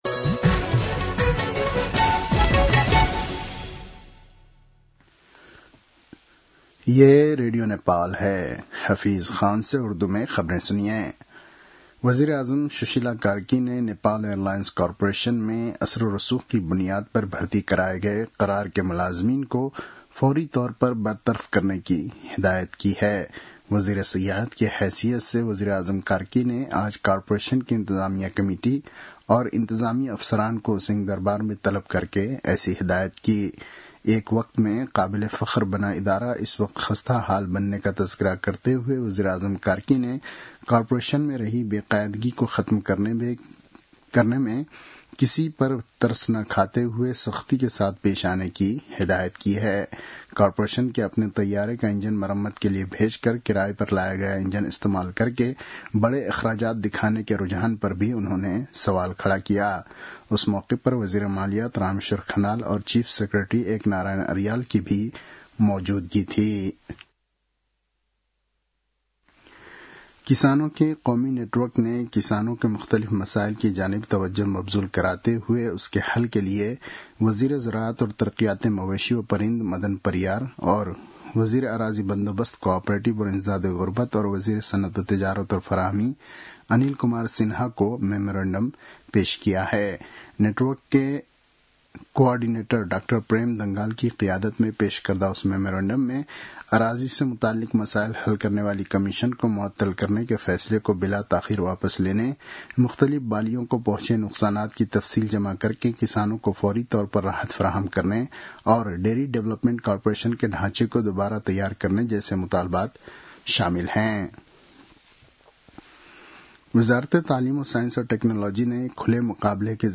उर्दु भाषामा समाचार : १६ कार्तिक , २०८२